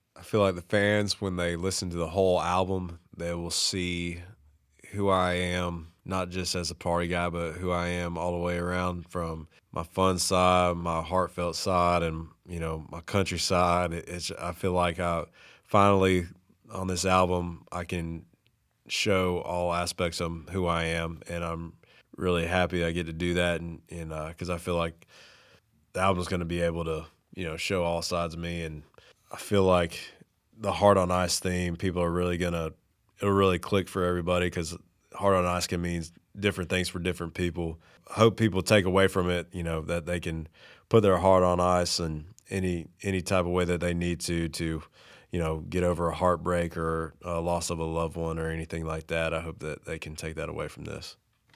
Audio / Jon Langston talks about his hopes for fans after they listen to his debut album, Heart On Ice.